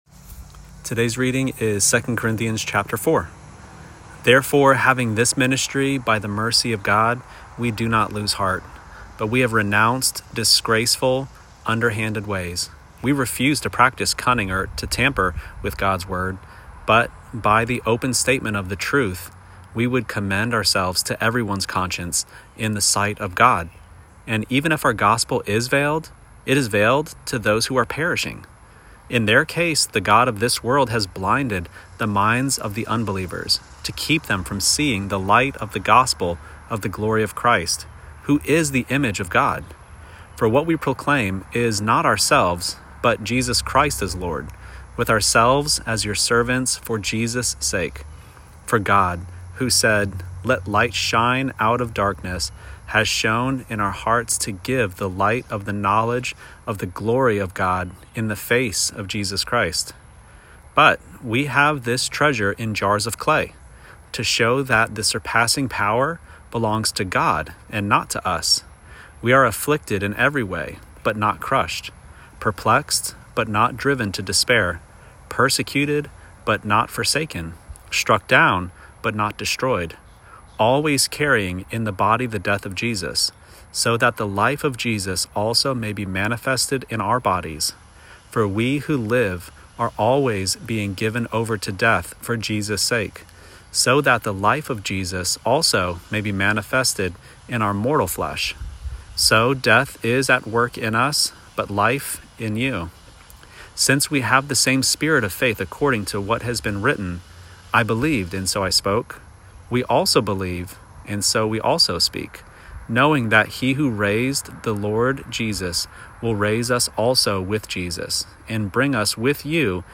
Daily Bible Reading (ESV)